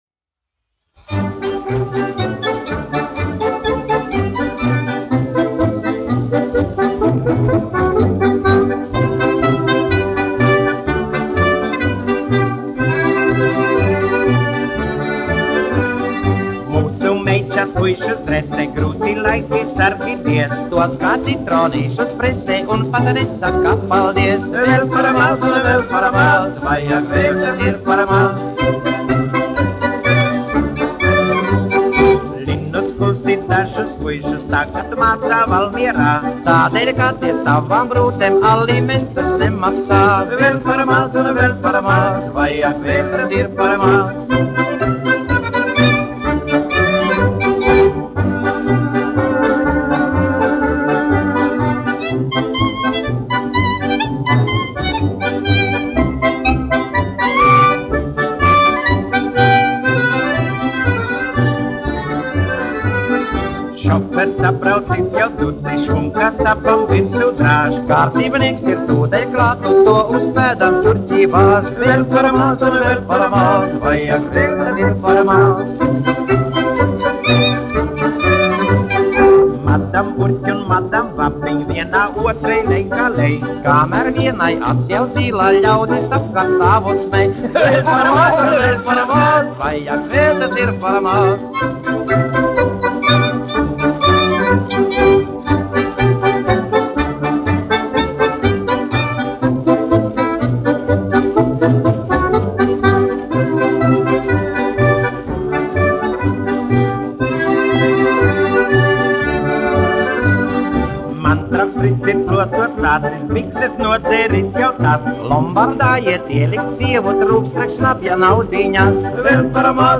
1 skpl. : analogs, 78 apgr/min, mono ; 25 cm
Populārā mūzika
Fokstroti
Latvijas vēsturiskie šellaka skaņuplašu ieraksti (Kolekcija)